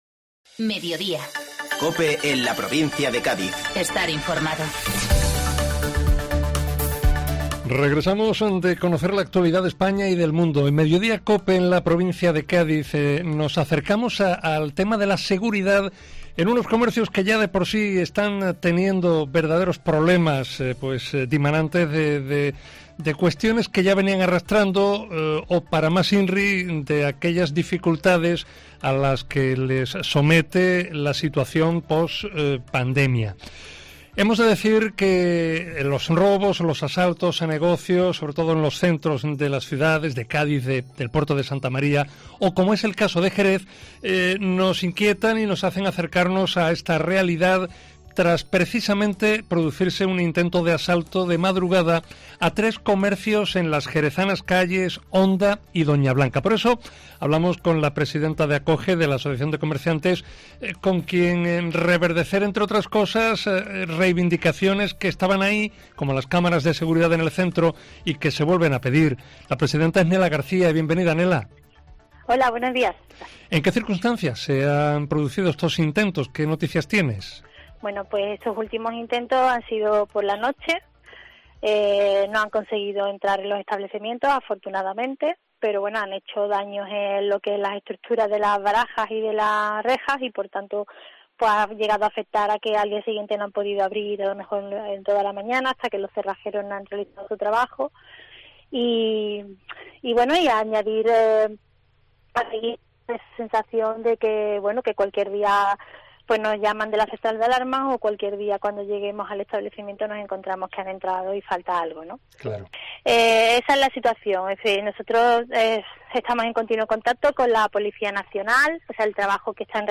Entrevista en Mediodía Cope Provincia de Cádiz